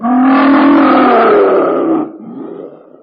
draugnorak/sounds/enemies/yeti1.ogg at af6b3e4e3e6616c1e64ba4e7b51dc3b1a2cb0472
yeti1.ogg